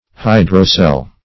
Hydrocele \Hy`dro*cele\, n. [L., fr. Gr.